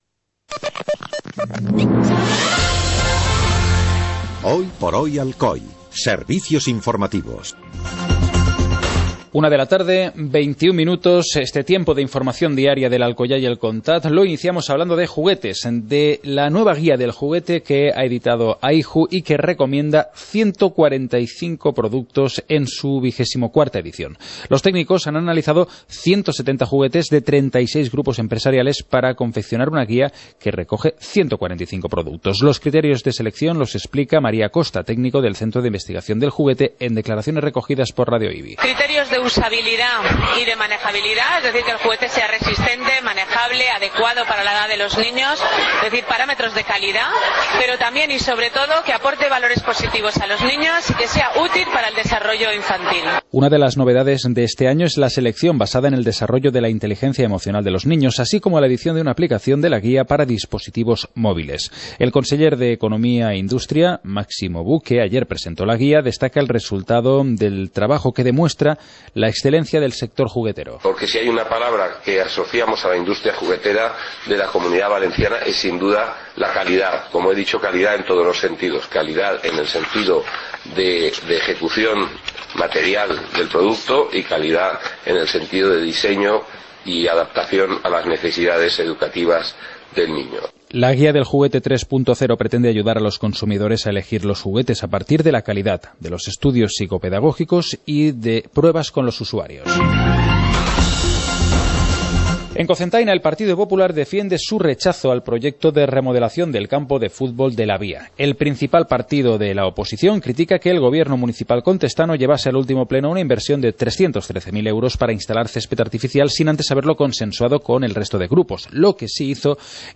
Informativo comarcal - martes, 18 de noviembre de 2014